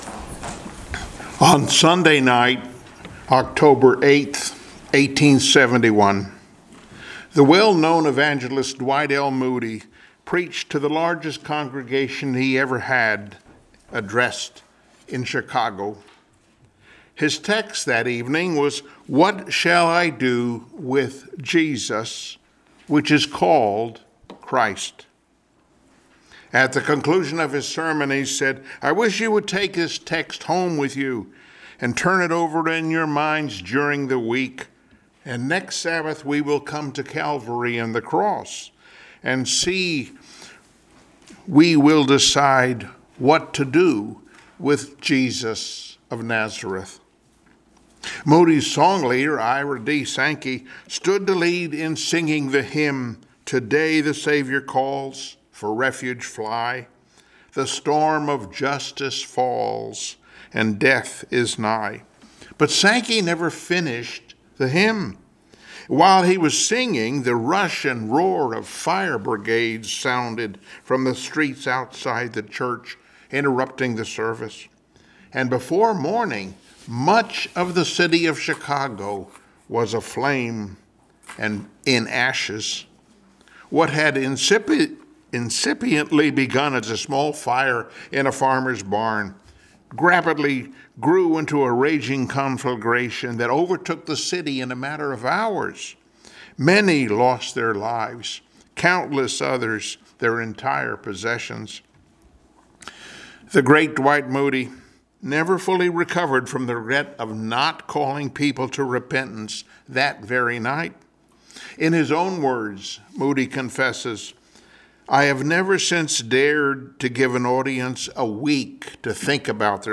Passage: Psalm 46 Service Type: Sunday Morning Worship Topics